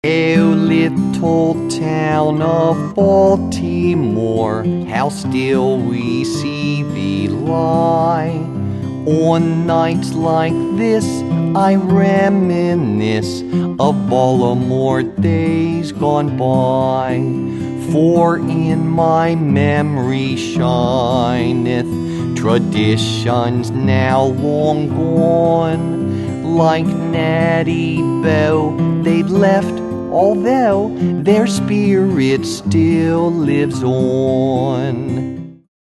This hilarious collection of novelty songs includes